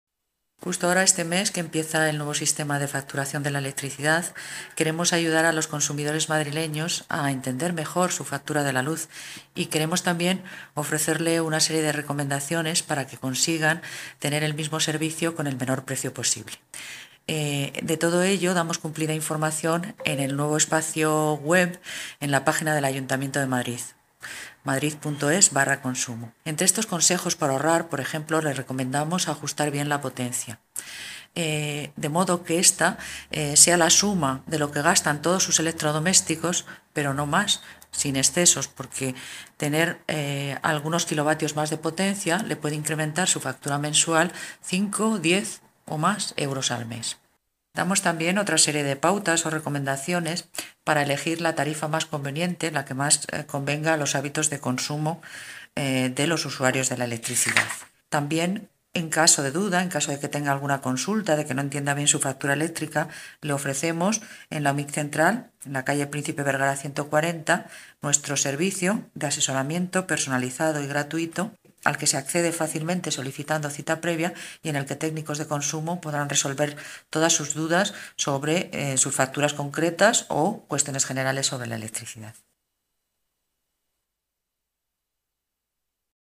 Nueva ventana:Carmen Robollo Sánchez, Directora General del Instituto Municipal de Consumo